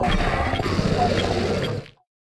Cri de Têtes-de-Fer dans Pokémon Écarlate et Violet.
Cri_0993_EV.ogg